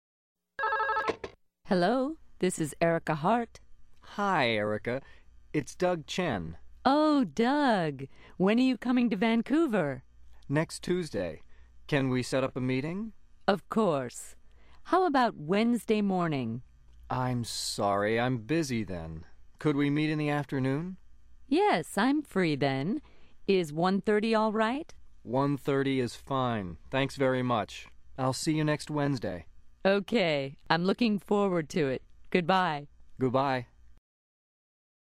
Listen to this telephone conversation for arranging a meeting and check the words and expressions you hear from the previous unit.